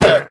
1.21.4 / assets / minecraft / sounds / mob / llama / hurt2.ogg
hurt2.ogg